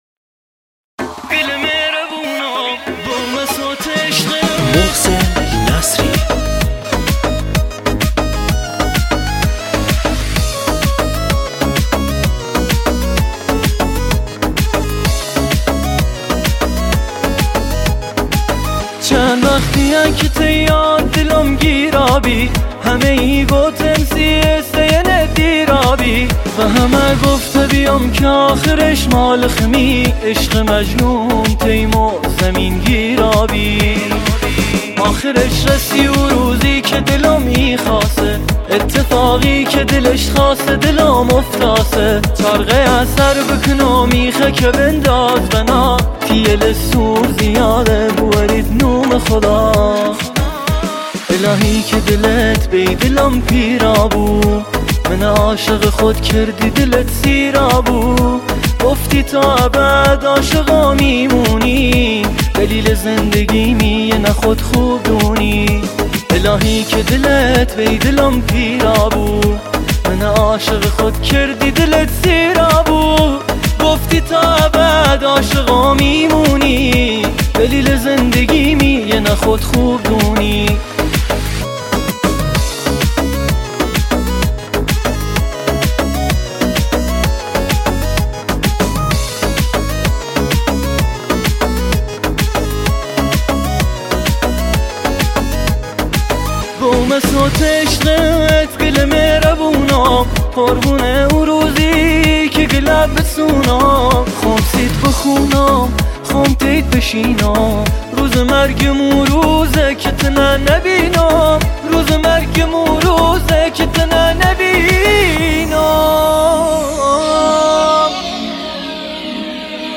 آهنگ لری جدید